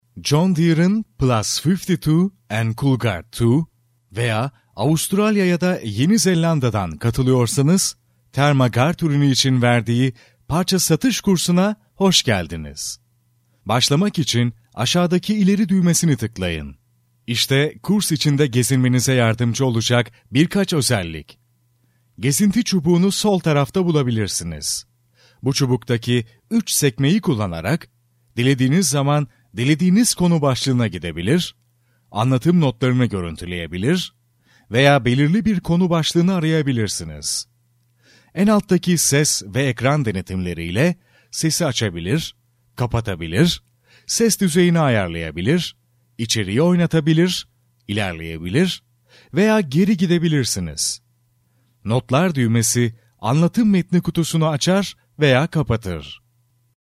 Masculino
Russian Travel Guide Documentary
From medium friendly read to articulate and energetic hard-sell.